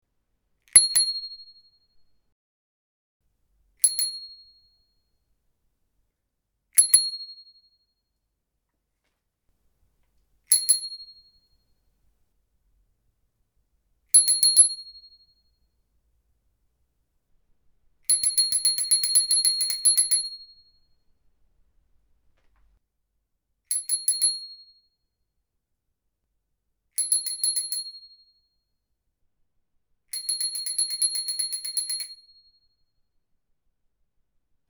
12. Дзынь-дзынь звук велозвонка (9 вариантов: ближе, дальше, 1 раз, 2, 3 и много)
velozvonok-din.mp3